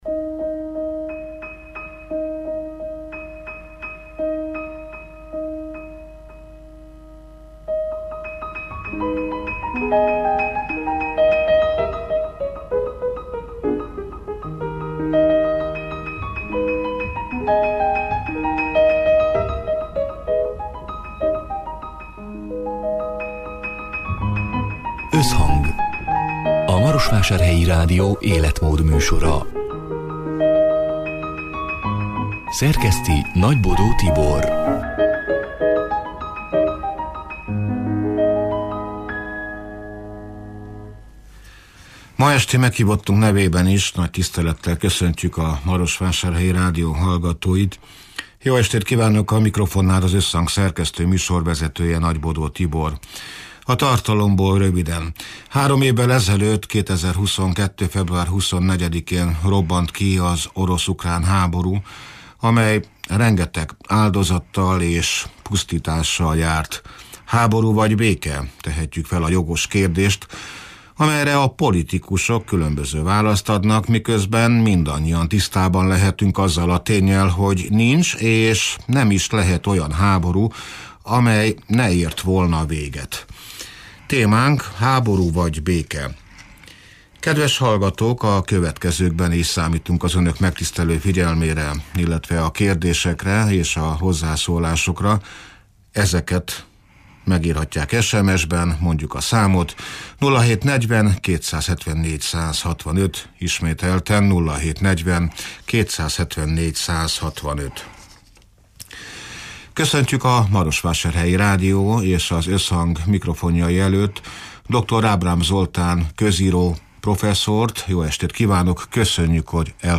(elhangzott: 2025. február 26-án, szerdán délután hat órától élőben)